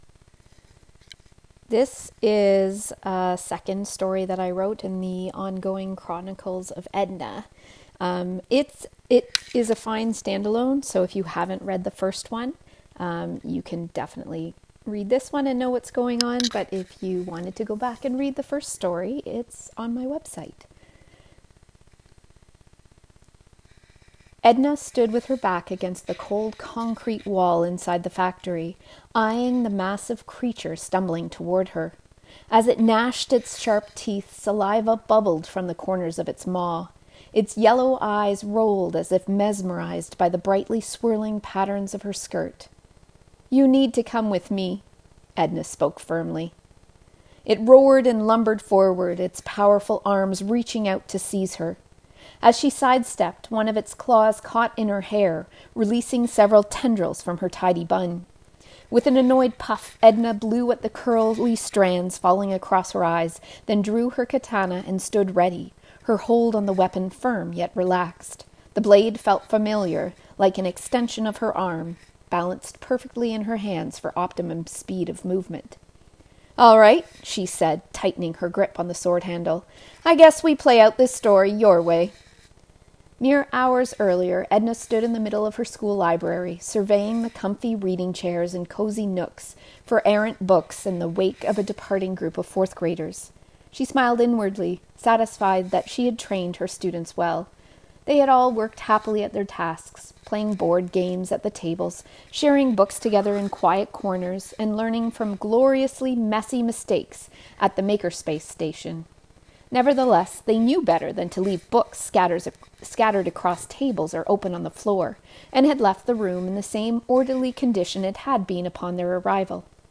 Here is the audio version of the second installment of the ongoing Chronicles of Edna* (if you haven't read the first, that's okay since her latest adventure can be enjoyed as a stand-alone).